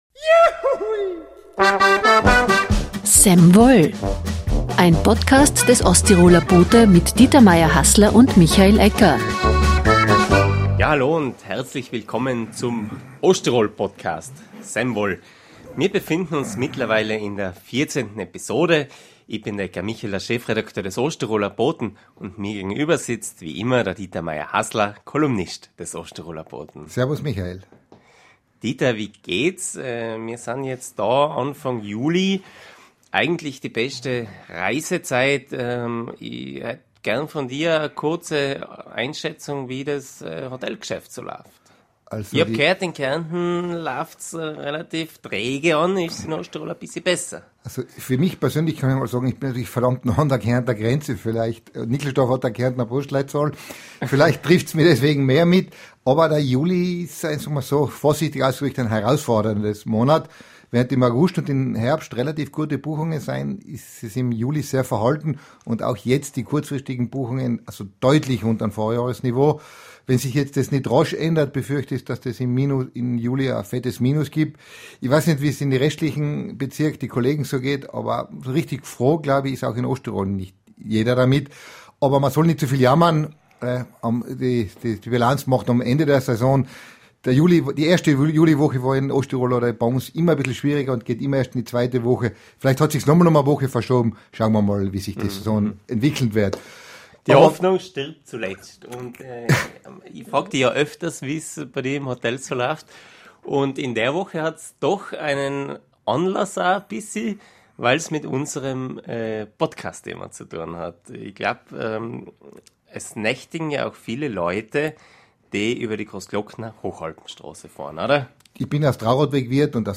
Eine Debatte über Radler auf den heimischen Straßen.